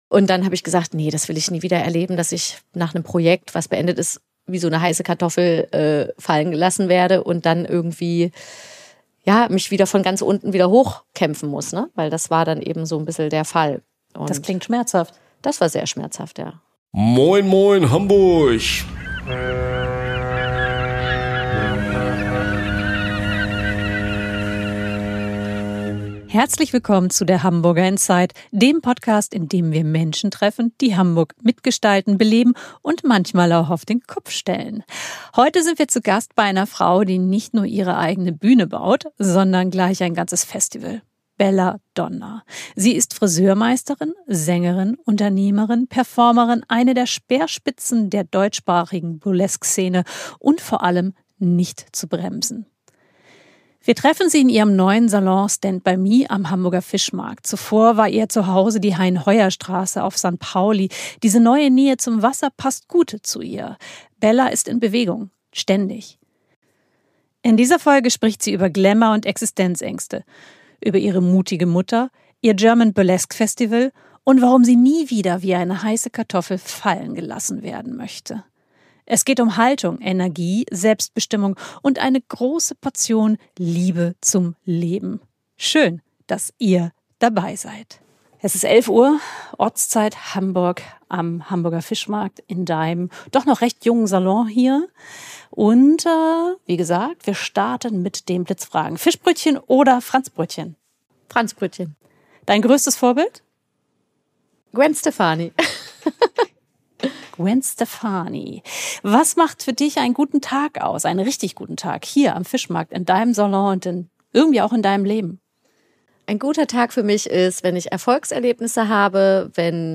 Burlesque, Business, Berlin?